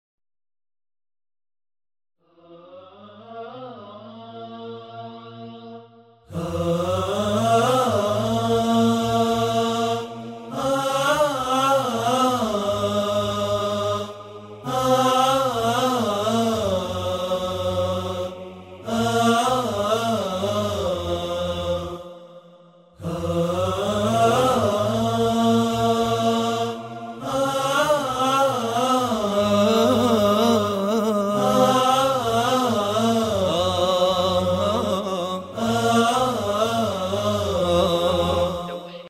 وکال مذهبی 12
وکال کوتاه جهت سوئیچ مراسمات و مجالس؛ مناسب جهت استفاده در ساخت کلیپ‌های مذهبی و آئینی؛